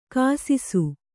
♪ kāsisu